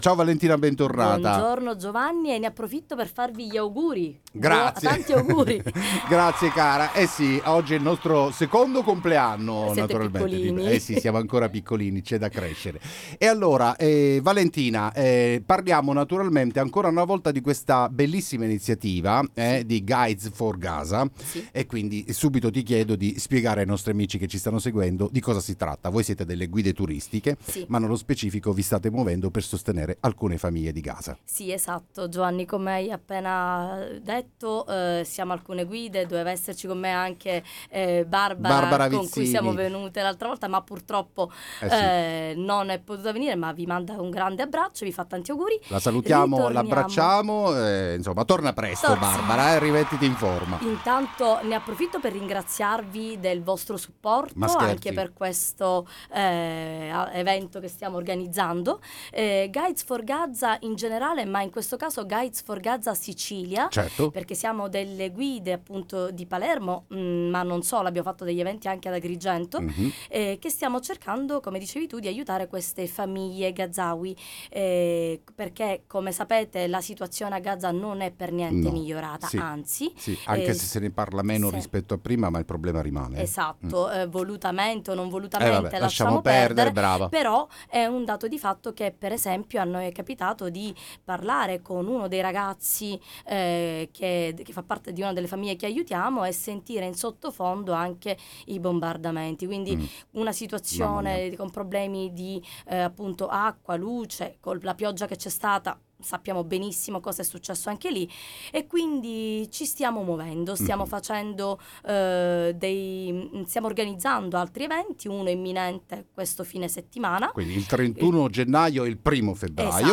Buongiorno da Marino Interviste